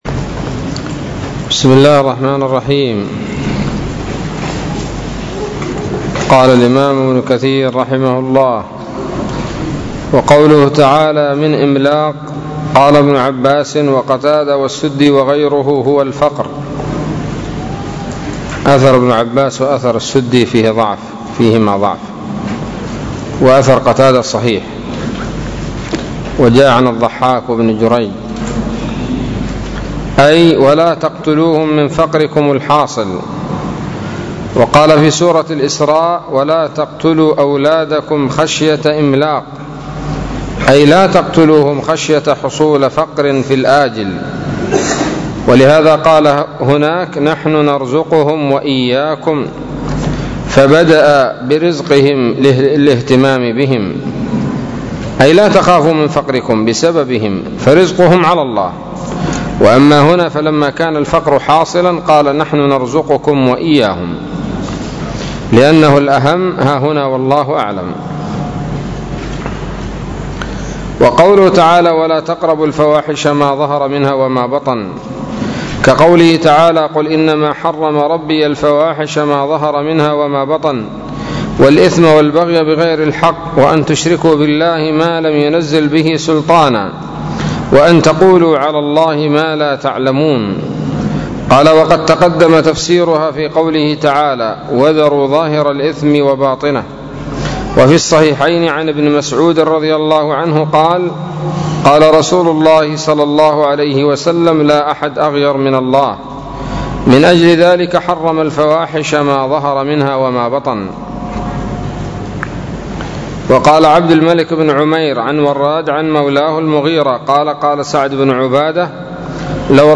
الدرس السادس والستون من سورة الأنعام من تفسير ابن كثير رحمه الله تعالى